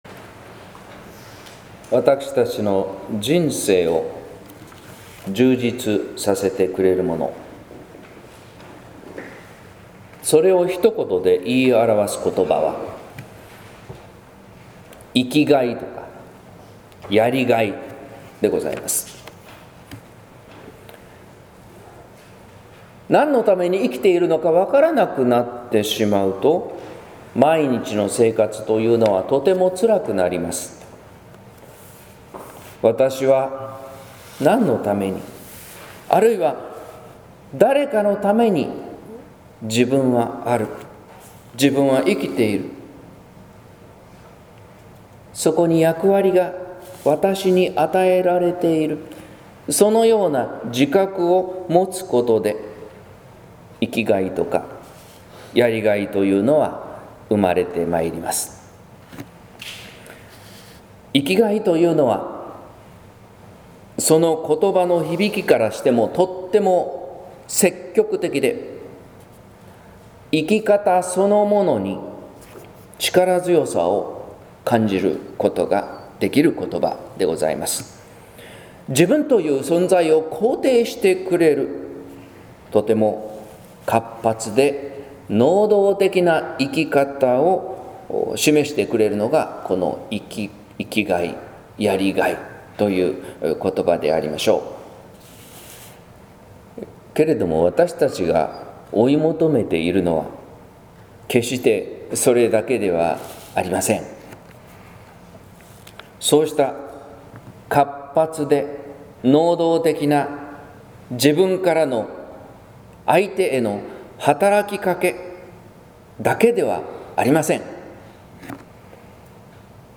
説教「ゆるしといやし」（音声版）